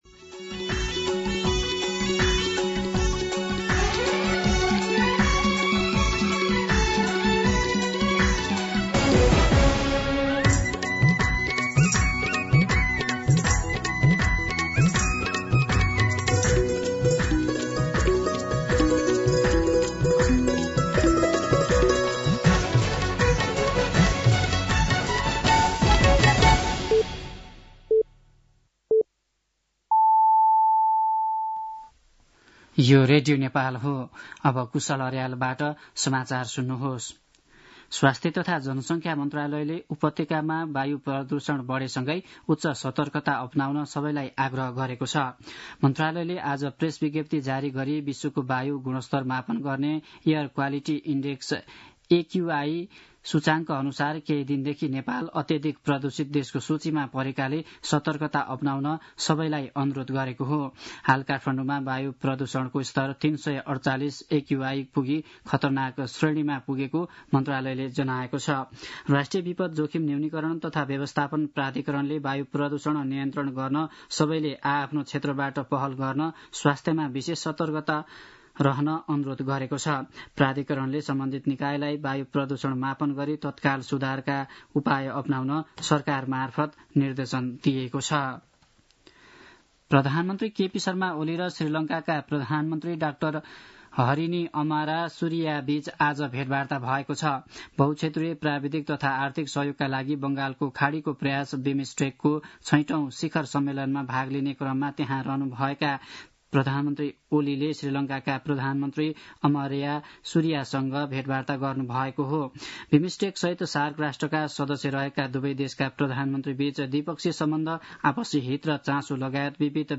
दिउँसो ४ बजेको नेपाली समाचार : २१ चैत , २०८१
4-pm-Nepali-News.mp3